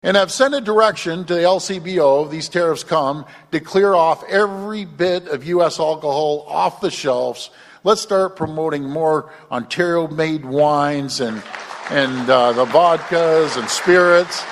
Speaking at the Rural Ontario Municipal Association conference in Toronto, Ford emphasized that Ontario is a large purchaser of alcohol and will be asking other premiers to follow in his footsteps.